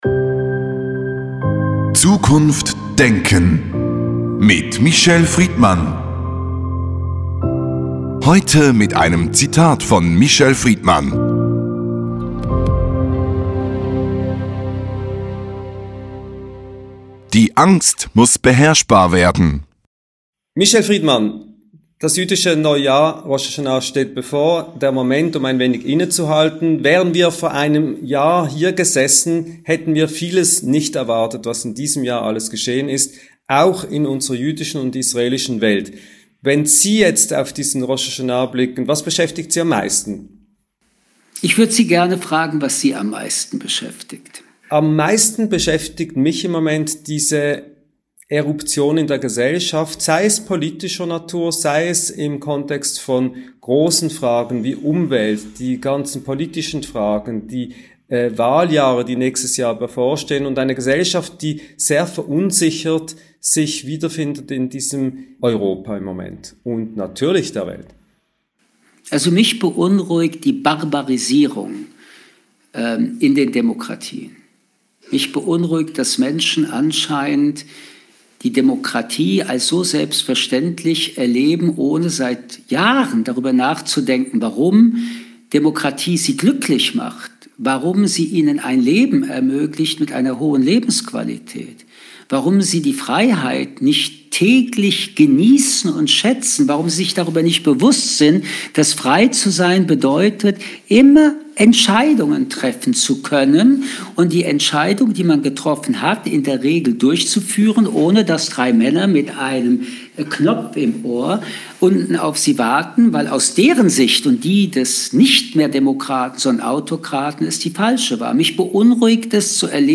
Das ausführliche Gespräch.